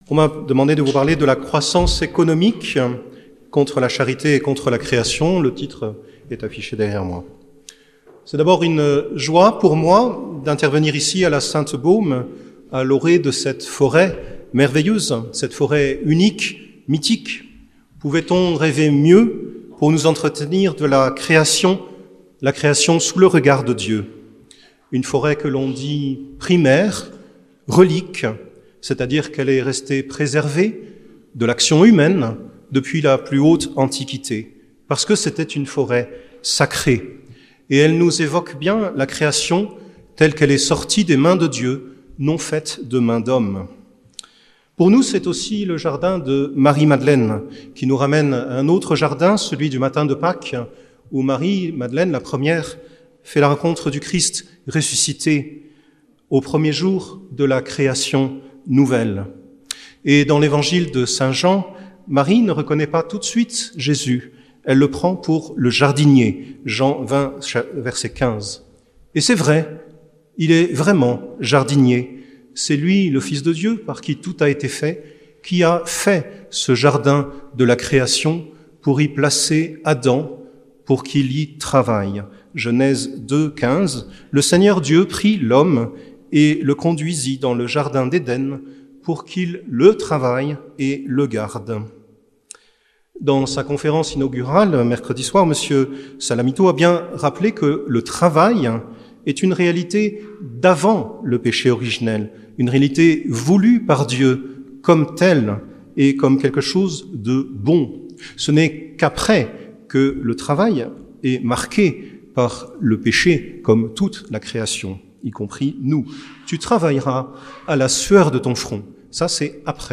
Université d'été